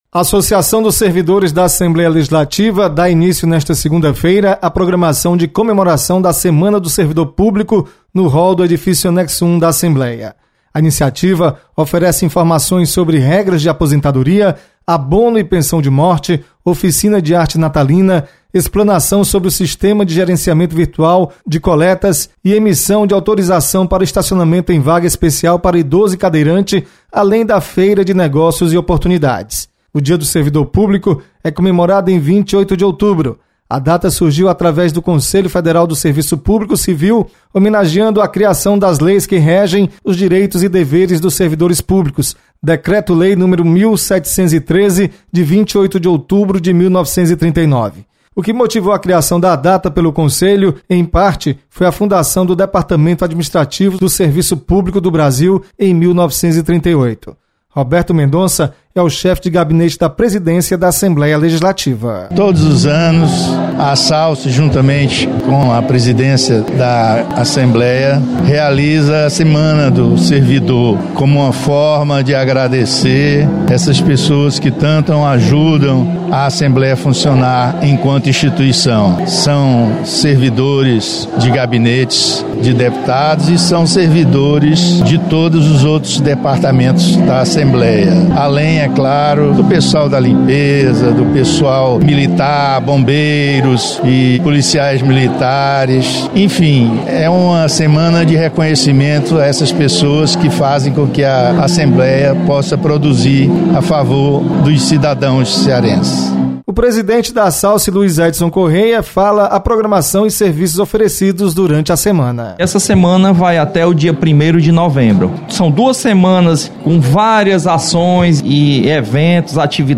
Assalce abre semana do servidor. Repórter